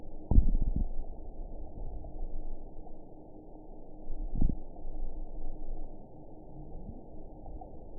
event 921960 date 12/23/24 time 08:48:03 GMT (11 months, 1 week ago) score 7.23 location TSS-AB03 detected by nrw target species NRW annotations +NRW Spectrogram: Frequency (kHz) vs. Time (s) audio not available .wav